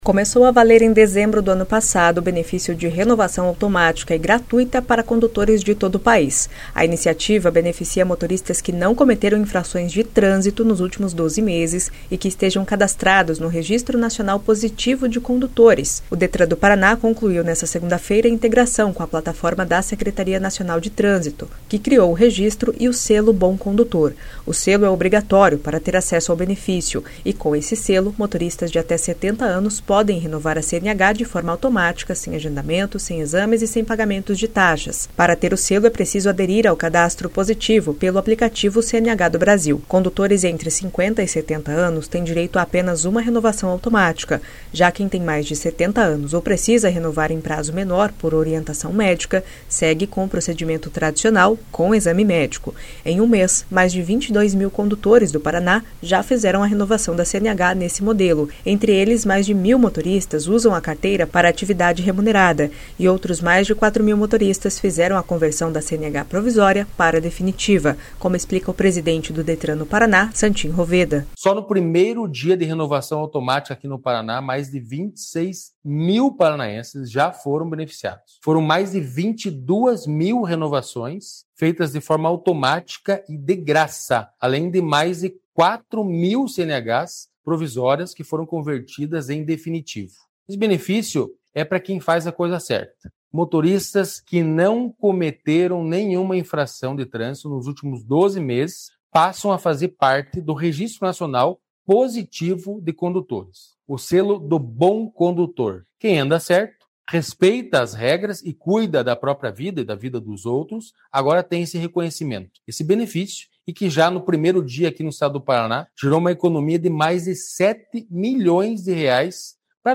Outros 4,1 mil motoristas fizeram a conversão da CNH provisória para a definitiva, como explica o presidente do Detran do Paraná, Santin Roveda.